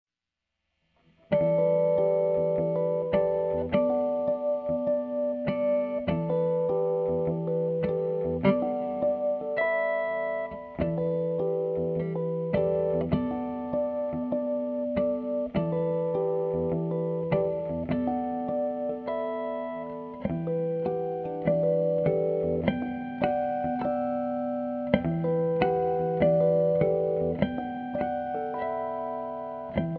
Guitar
Two Electric Guitar Entwine
Lyrical Jazz, Blues and Rock inflected Duets